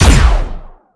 fire_gun1_rank4.wav